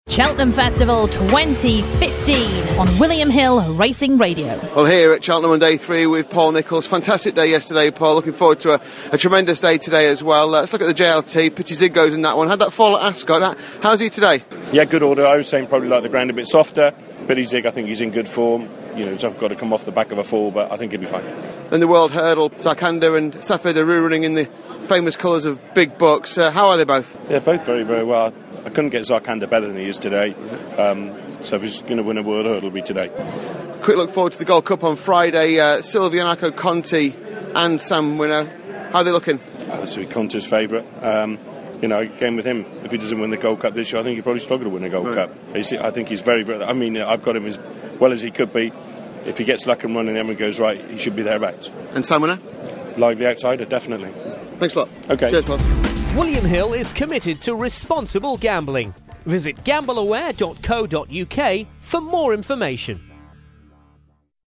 Trainer Paul Nicholls talks about his hopes for three of his horses on Day Three and the Gold Cup on Friday